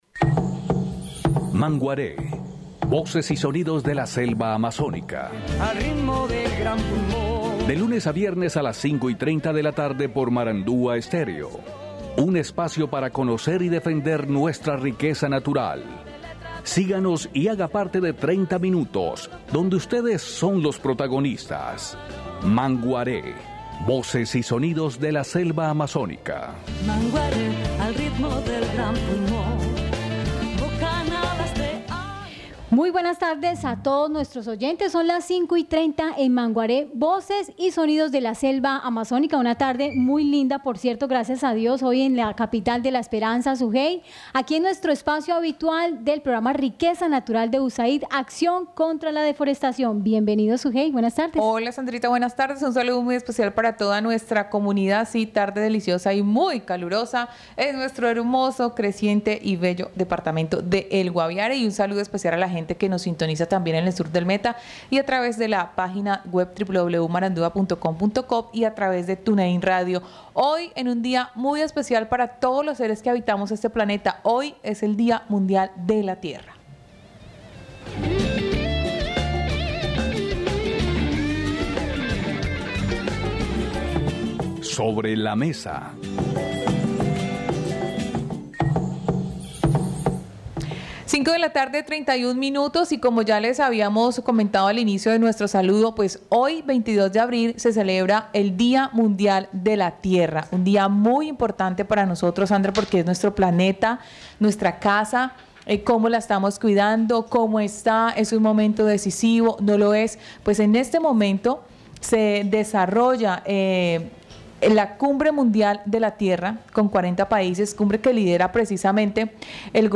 En el Día Mundial de la Tierra, 40 líderes mundiales se reúnen durante el 22 y 23 de abril en la ‘Cumbre Climática 2021’ liderada por EEUU. Escuché aquí intervención del presidente Iván Duque y los compromisos frente a la reducción de gases efecto invernadero y cambio climático.